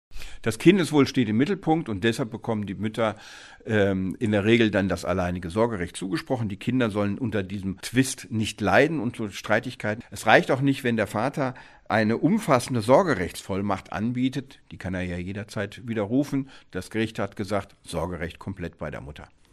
DAV, O-Töne / Radiobeiträge, Ratgeber, Recht, , , , , , ,